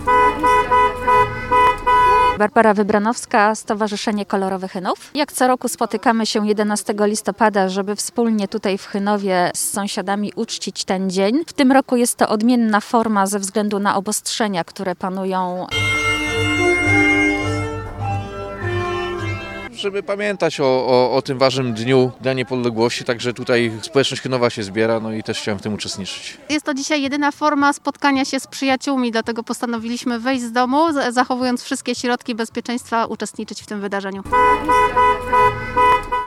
Kilkadziesiąt samochodów wyposażonych w biało-czerwone flagi, patriotyczne pieśni z głośników i odśpiewanie Mazurka Dąbrowskiego – tak wyglądało świętowanie 11 listopada w zielonogórskim Chynowie.
Konwój mieszkańców w samochodach wyruszył sprzed boiska piłkarskiego Chynowianki i przejechał ulicami sołectwa do ul. Łężyckiej – tam zielonogórzanie punktualnie o 12:00 odśpiewali hymn państwowy: